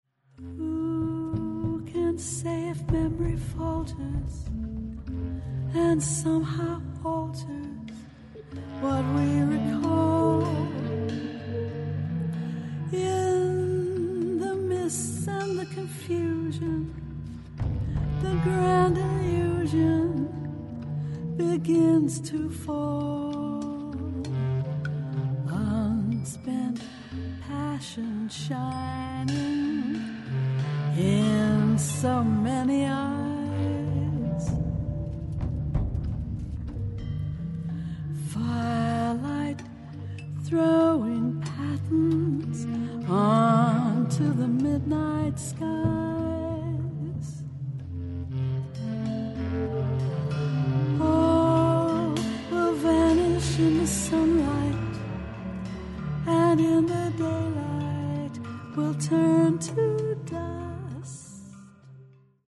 Recorded March 2017, ArteSuono Studio, Udine